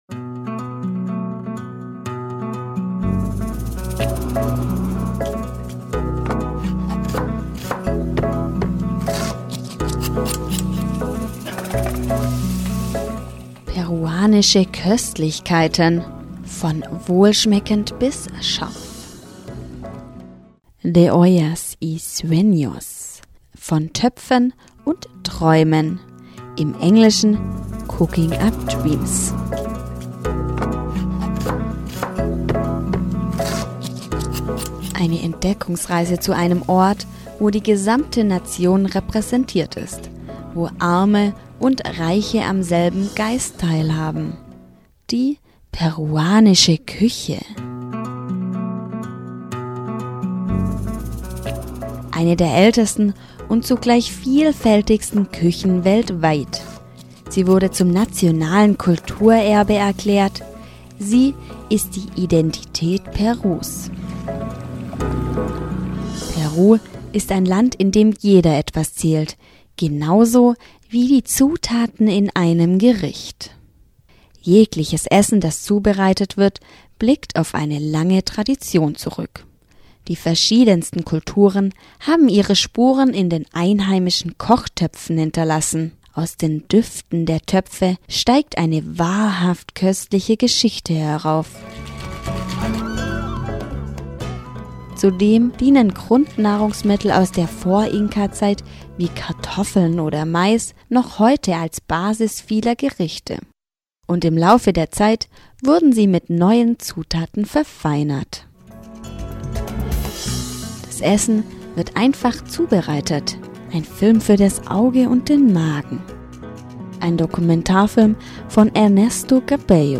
Rezension: De ollas y sueños (Von Töpfen und Träumen)